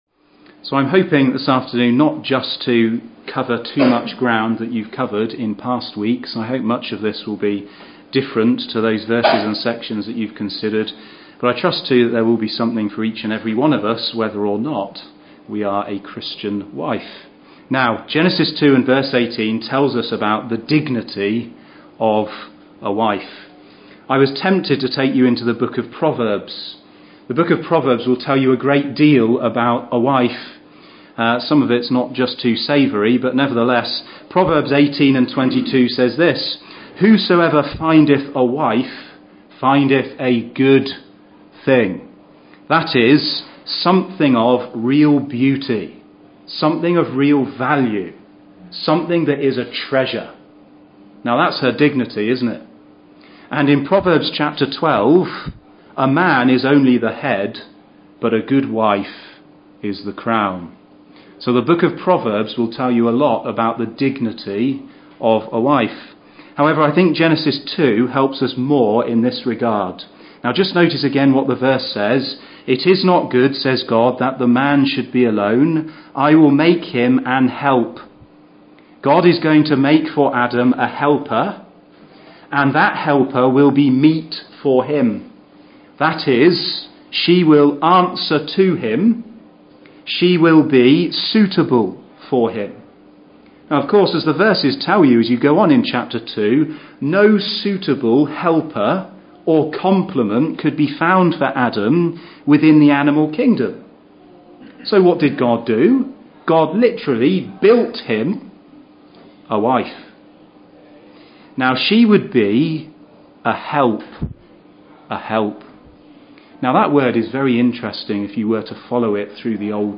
He explains the scriptural meaning of equality, submission and virtue, and addresses pertinent points relative to how modern culture throws up challenges for the married Christian woman (Message preached 7th Feb 2016)